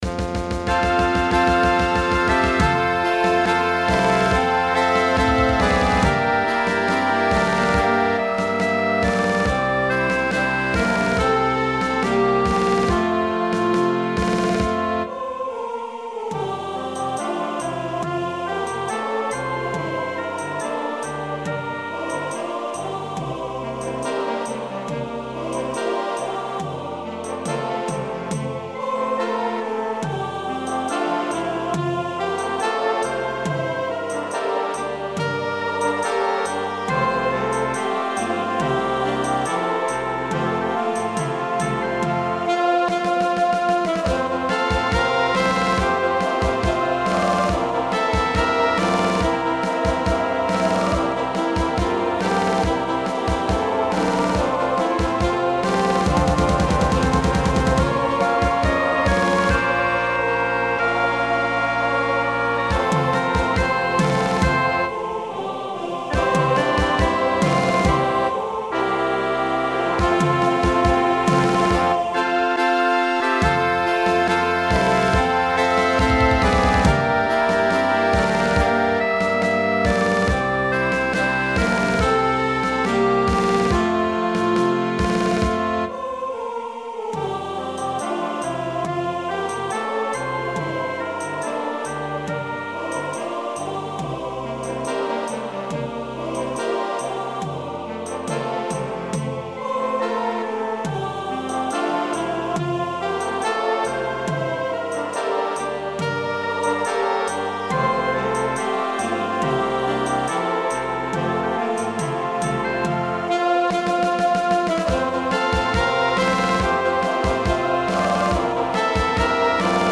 O SOLE MIO Classico napoletano E.Di Capua